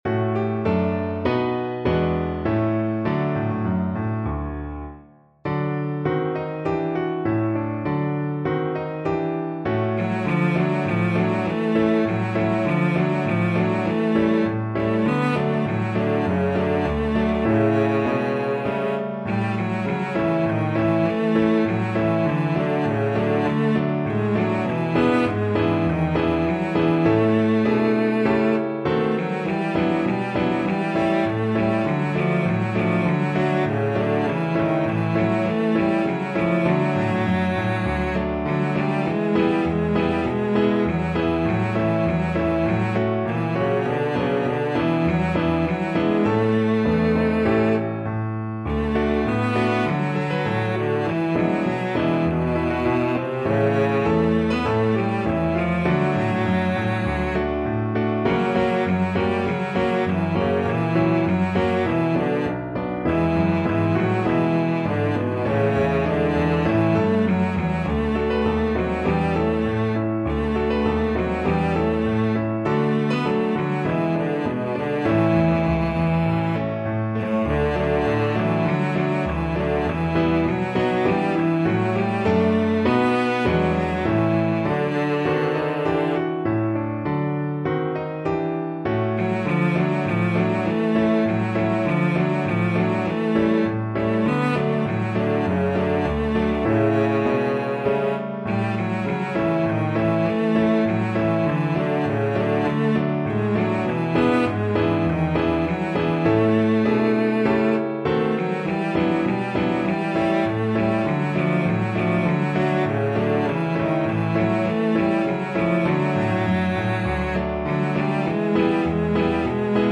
Moderato = c.100
Classical (View more Classical Cello Music)